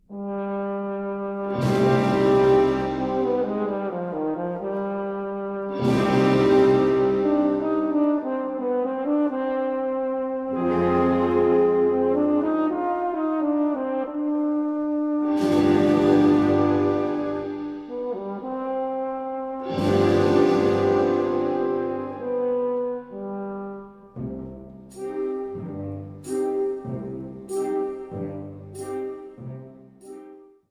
Category Concert/wind/brass band
Subcategory Concert music
Instrumentation Ha (concert/wind band)